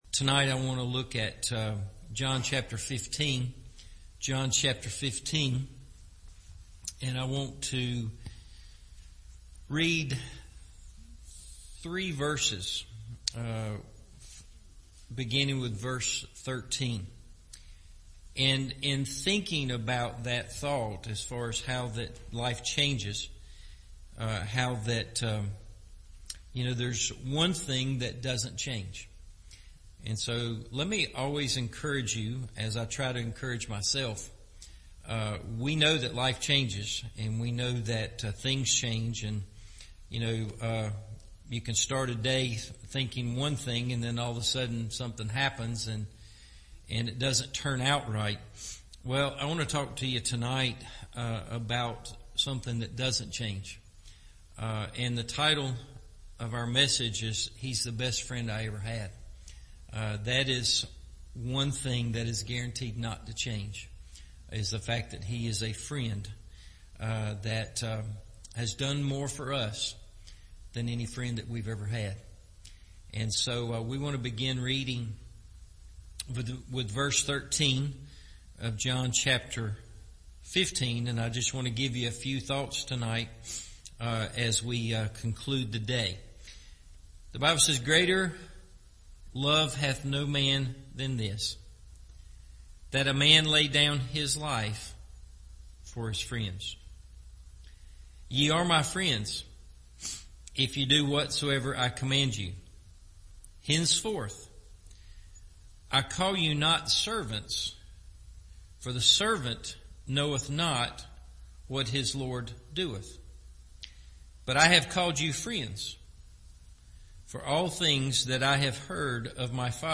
He’s The Best Friend I Ever Had – Evening Service